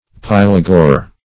Search Result for " pylagore" : The Collaborative International Dictionary of English v.0.48: Pylagore \Pyl"a*gore\, n. [Gr.